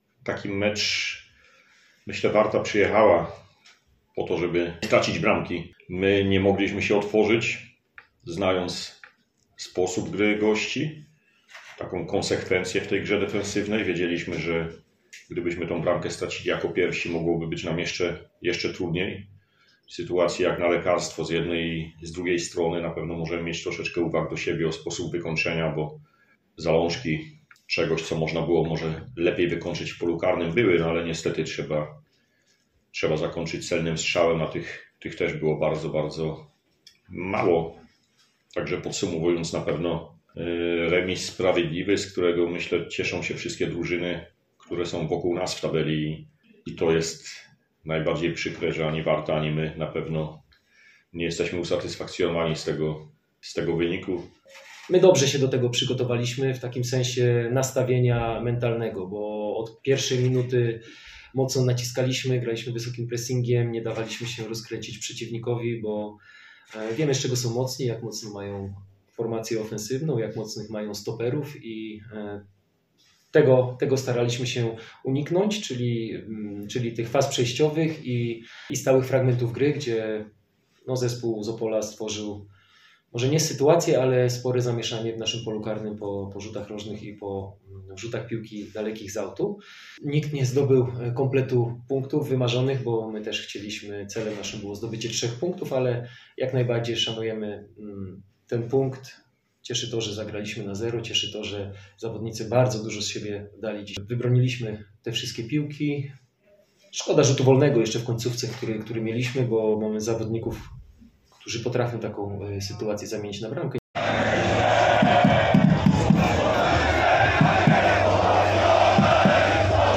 Jej repertuar był bardzo bogaty – co można usłyszeć w poniższym dźwięku – dodatkowo dwukrotnie wykorzystano race do wzbogacenia dopingu, co kończyło się zadymieniem stadionu i krótkimi przerwami w grze.
Mimo nieco nudnego meczu, na trybunach nie ustawały różnego rodzaju przyśpiewki, nie tylko z udziałem zorganizowanej grupy kibicowskiej, ale i całego stadionu.
Relacja-Odra.mp3